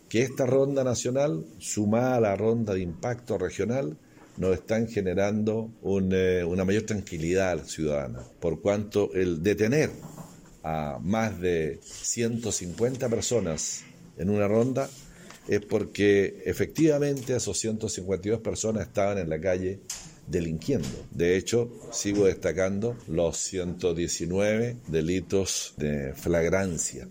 Asimismo, el Intendente Harry Jürgensen señaló que esta ronda nacional sumada a la regional han generado mayor tranquilidad al ciudadano.